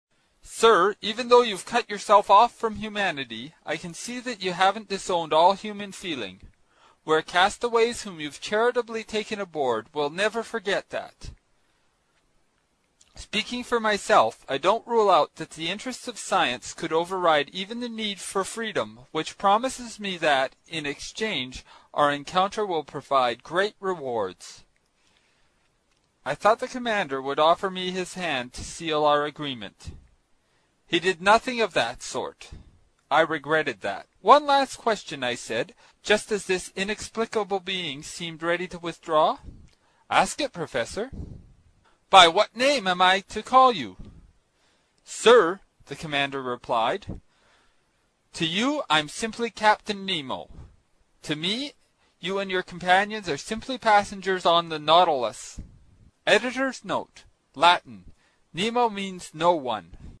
英语听书《海底两万里》第142期 第10章 水中人(13) 听力文件下载—在线英语听力室
在线英语听力室英语听书《海底两万里》第142期 第10章 水中人(13)的听力文件下载,《海底两万里》中英双语有声读物附MP3下载